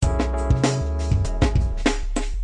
Drumloop98bpm2
描述：我在flstudio中制作的鼓循环。